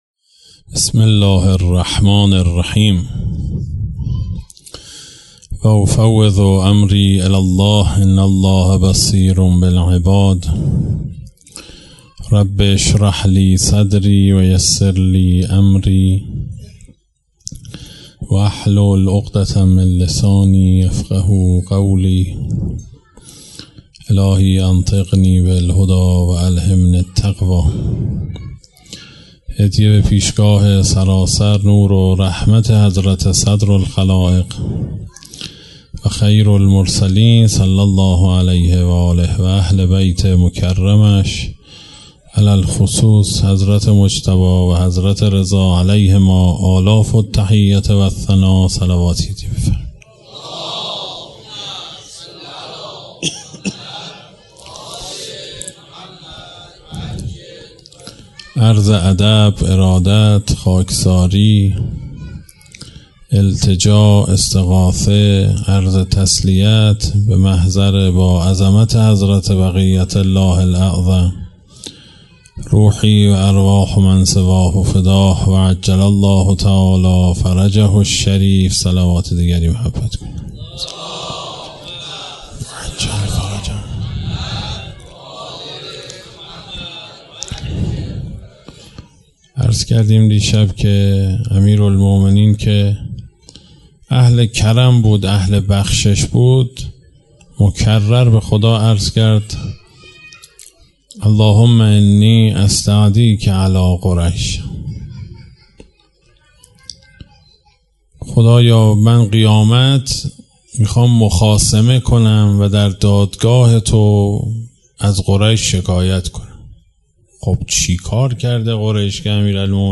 اشتراک گذاری دسته: امام حسین علیه السلام , بعضی از انحرافات بنیادین سقیفه , سخنرانی ها , محرم و صفر قبلی قبلی بعضی از انحرافات بنیادین سقیفه؛ جلسه 1 بعدی بعضی از انحرافات بنیادین سقیفه؛ جلسه 3 بعدی